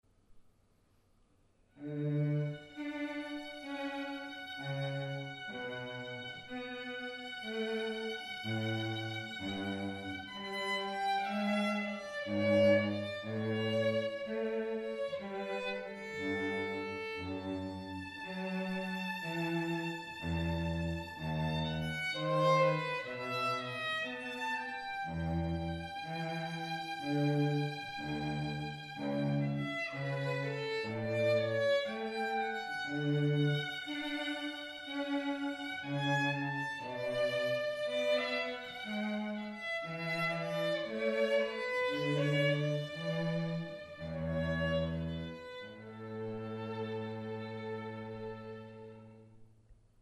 Wedding & Classical